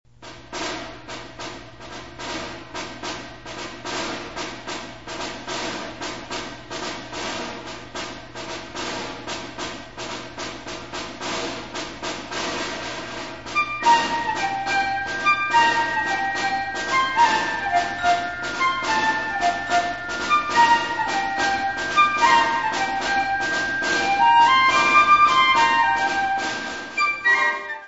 Catégorie Harmonie/Fanfare/Brass-band
Instrumentation Ha (orchestre d'harmonie)
est caractérisée par des flûtes et des tambours